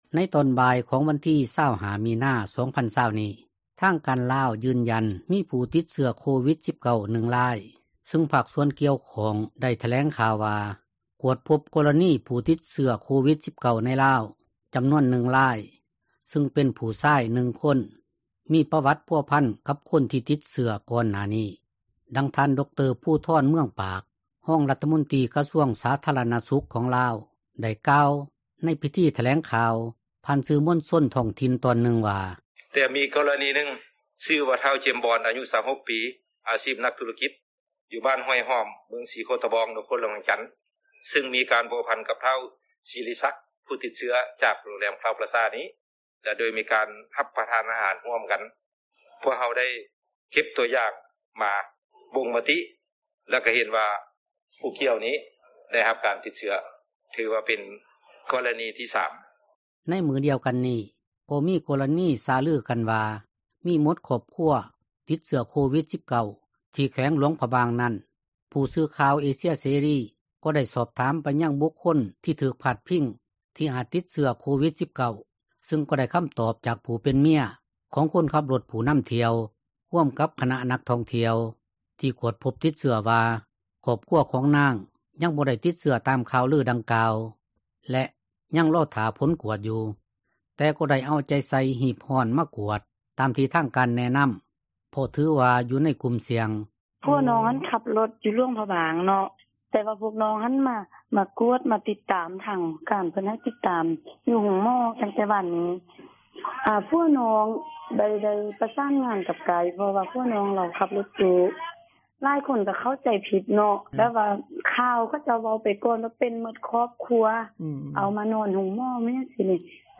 ດັ່ງທ່ານ ດຣ. ພູທອນ ເມືອງປາກ, ຮອງຣັຖມົນຕຣີ ກະຊວງສາທາຣະນະສຸຂ ຂອງລາວ ໄດ້ກ່າວ ໃນພິທີຖແລງຂ່າວ ຜ່ານ ສື່ມວນຊົນ ທ້ອງຖິ່ນຕອນນຶ່ງວ່າ: